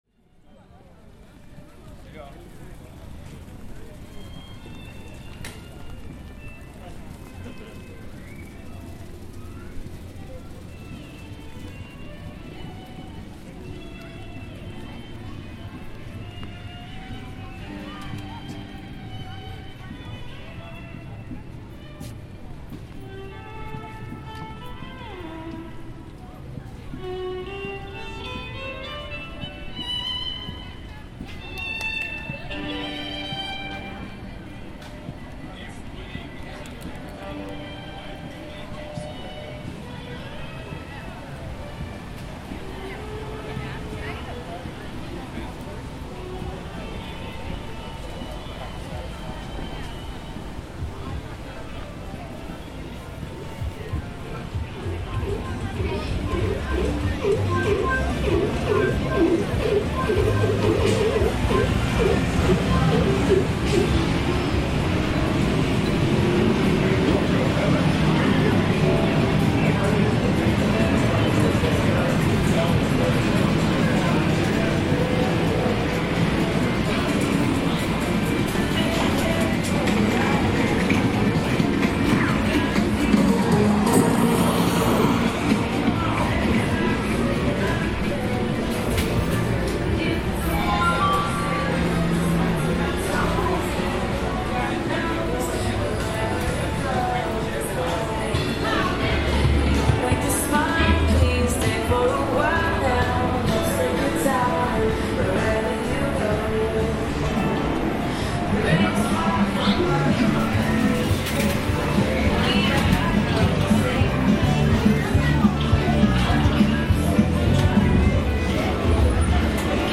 Our walk takes us along the boardwalk in downtown Ocean City, MD. We start hearing the calming sound of a busking violin player on the boardwalk playing for fun and money.
The ambience is light and contrasts the overwhelming remainder of the recording, when we enter the arcade Fun City. Here we hear the sound effects of many video games and ticket-winning challenges overlapping, despite being less than 30 feet from the peaceful boardwalk that we had just left.